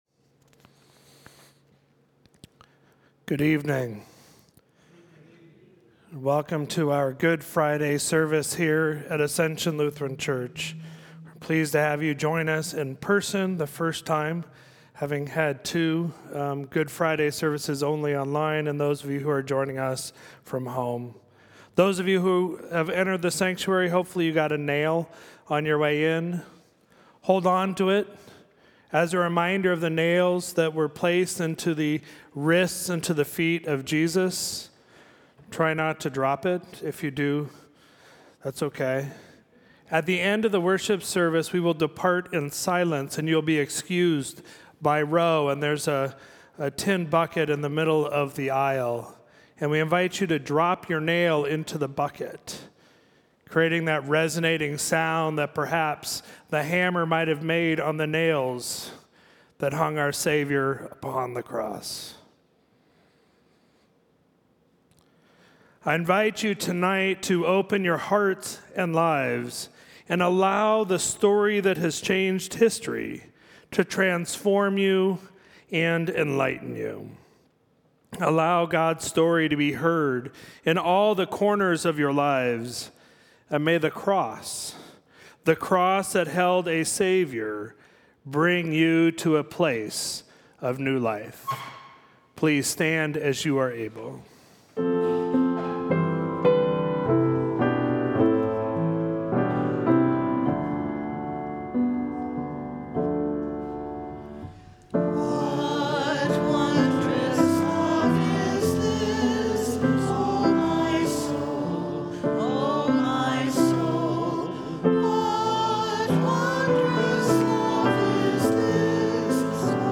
Worship for Friday, April 15, 2022